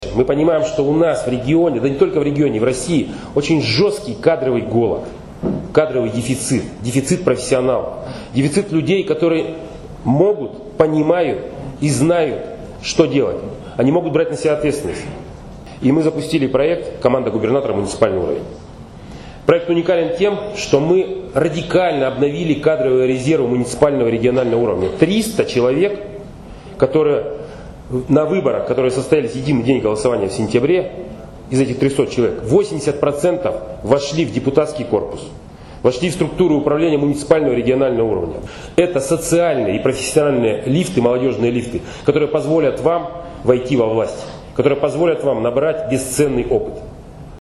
Олег Кувшинников о проекте «Команда губернатора: муниципальный уровень»
Первый съезд молодежных парламентов области состоялся в Вологде
На съезде присутствовал губернатор Олег Кувшинников